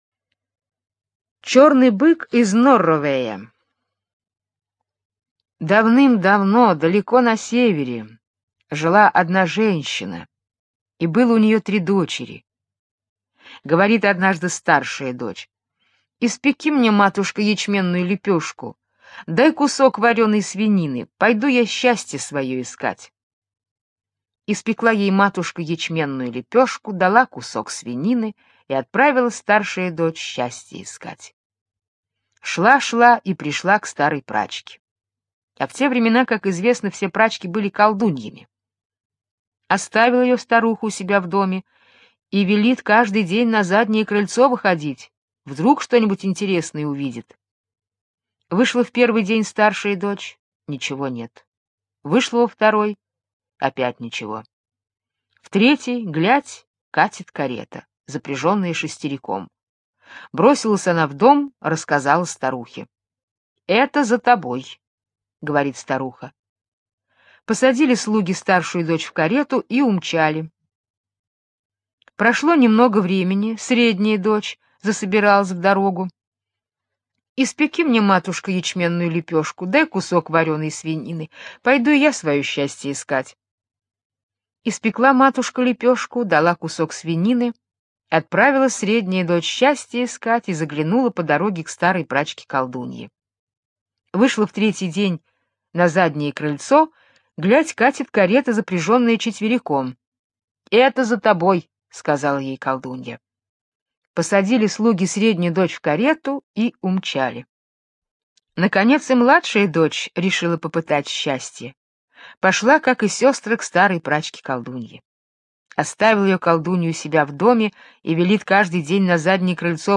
Черный бык из Норровея - британская аудиосказка - слушать онлайн